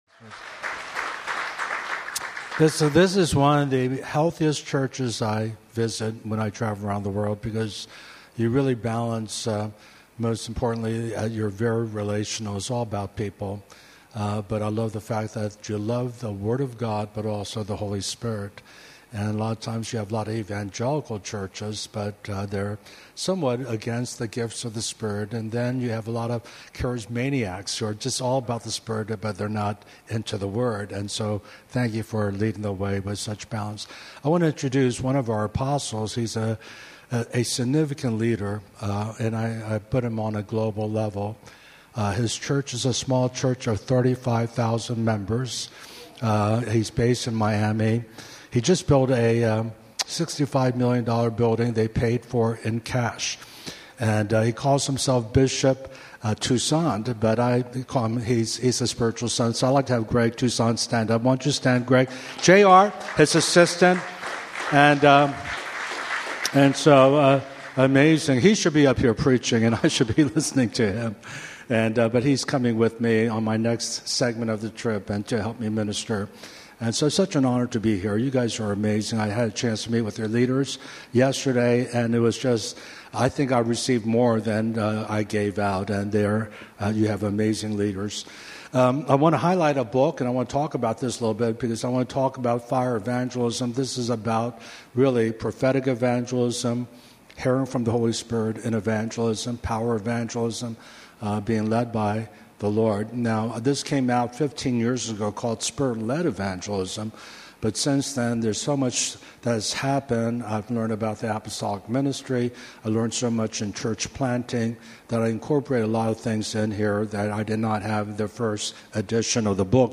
Sermons from Solomon's Porch Hong Kong.